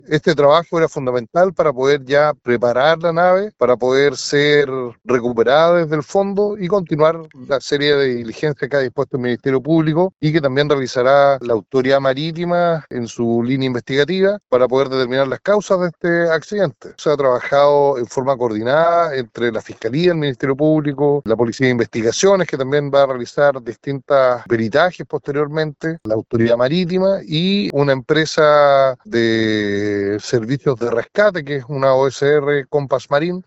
El gobernador marítimo, Mario Besoain, confirmó el término de las maniobras, precisando que se extrajo combustible y otros líquidos.
gobernador-maritimo.mp3